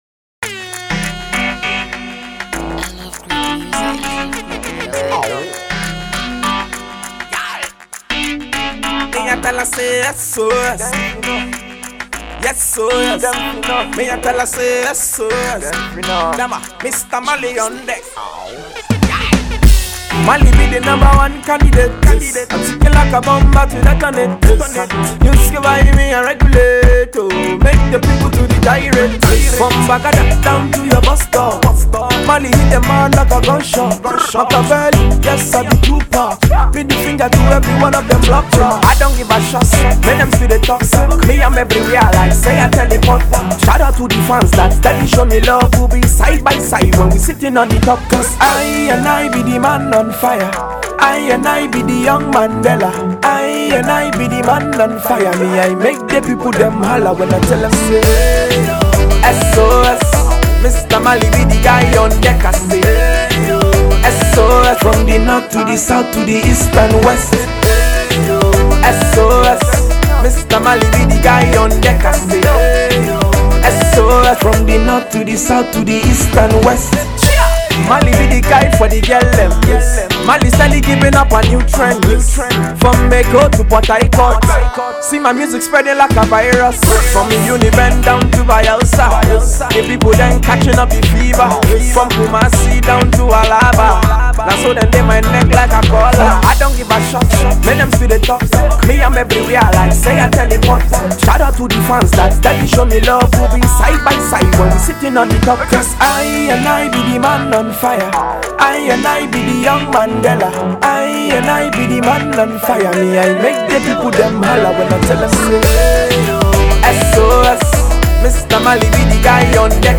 On his groovy new single